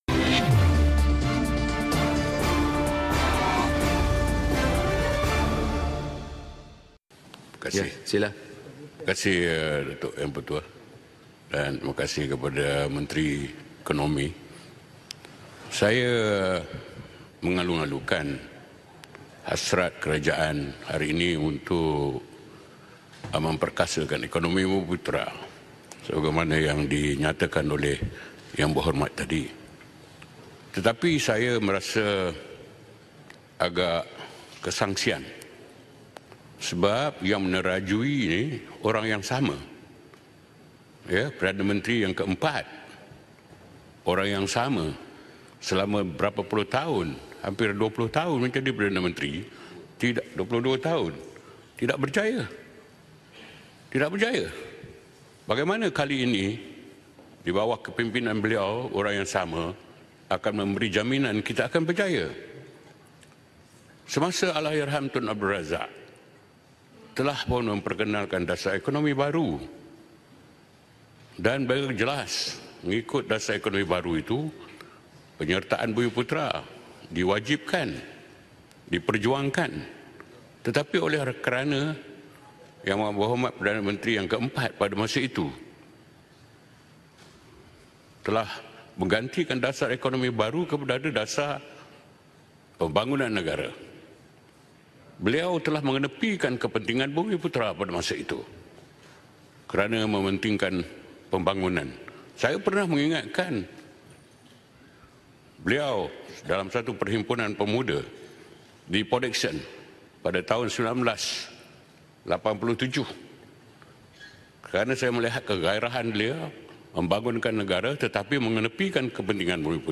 Ahli Parlimen Pasir Salak Datuk Seri Tajuddin Abdul Rahman bangkit bertanyakan soalan kepada Menteri Hal Ehwal Ekonomi Datuk Seri Mohamed Azmin Ali berkenaan agenda kerajaan Persekutuan untuk memperkasakan ekonomi bumiputera.